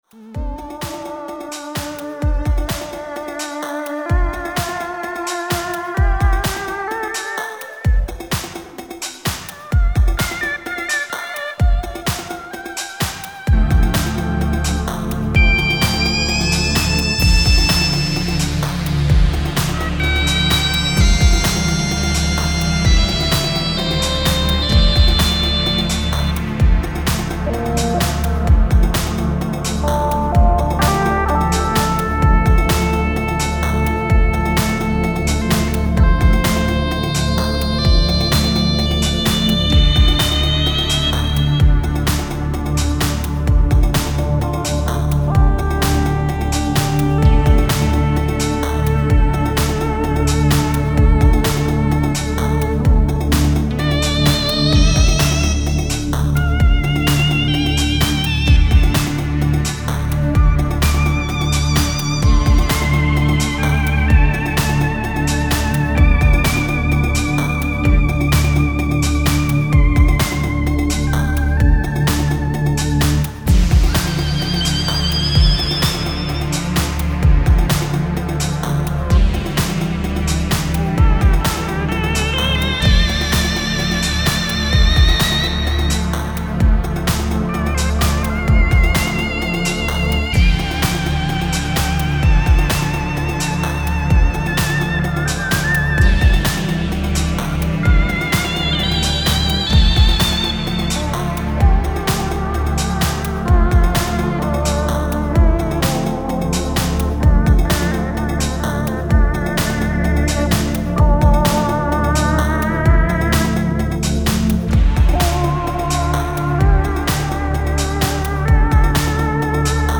Techno & Rave